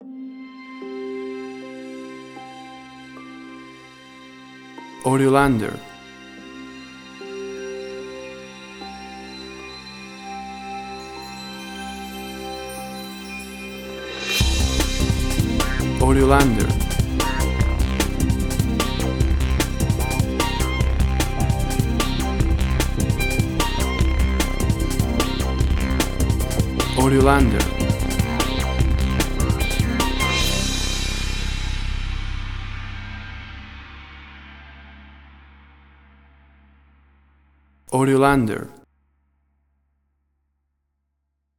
Hi energy, electronics and ethnics. Druma and bass
Tempo (BPM): 160